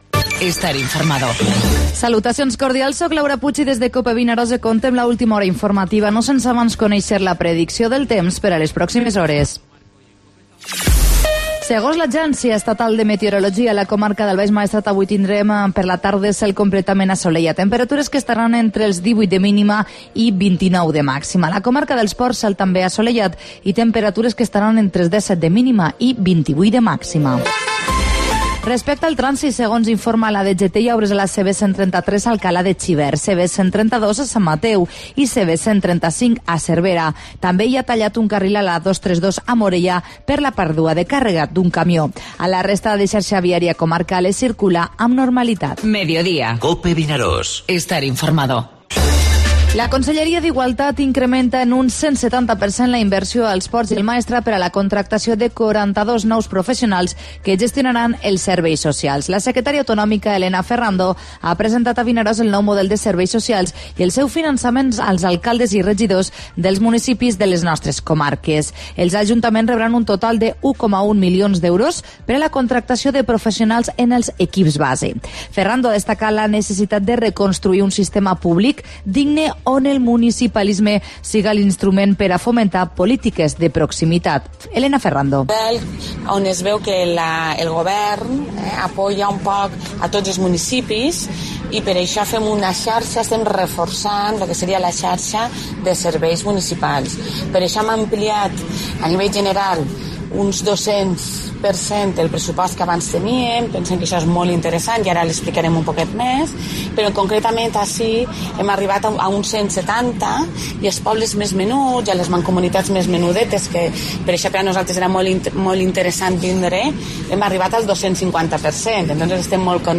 Informativo Mediodía COPE al Maestrat (20/6/17)